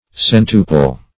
Search Result for " centuple" : The Collaborative International Dictionary of English v.0.48: Centuple \Cen"tu*ple\, a. [L. centuplex; centum + plicare to fold; cf. F. centuple.]